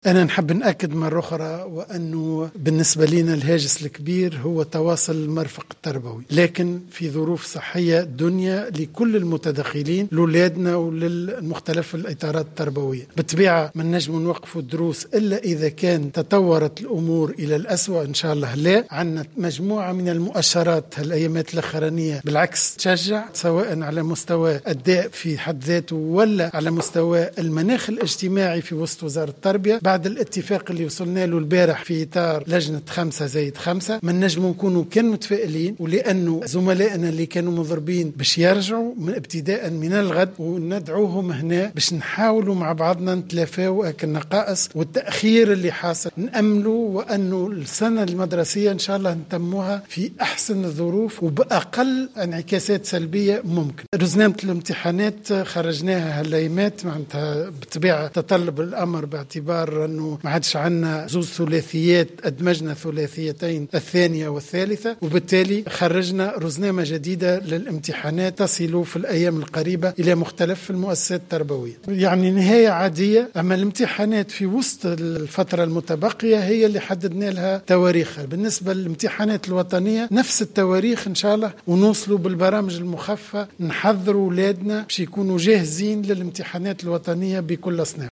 قال وزير التربية، فتحي السلاوتي في تصريح لمراسلة الجوهرة "اف ام" اليوم الاحد بالحمامات إن الهاجس الكبير للوزارة هو تواصل المرفق التربوي لكن في ظروف صحية دنيا لكل المتدخلين و لا يمكن ايقاف الدروس إلا إذا تطورت الأمور للأسوء حسب قوله.